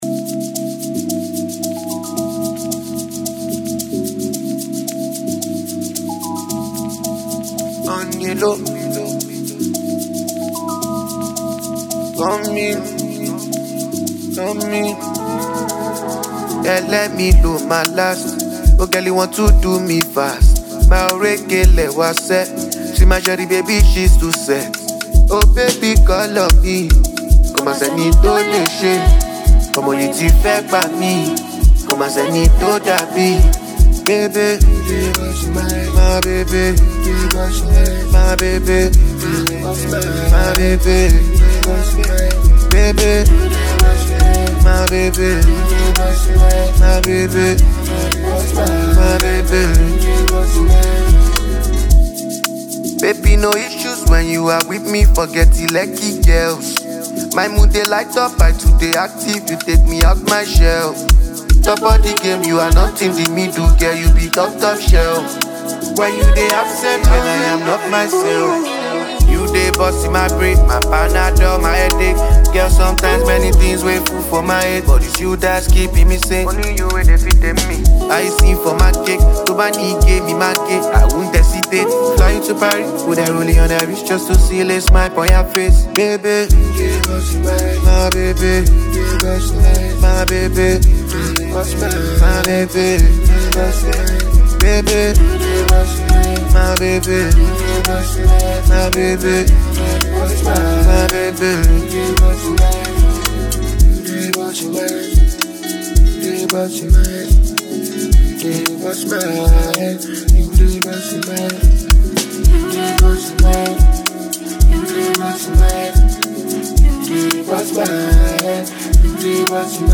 Ghanaian singer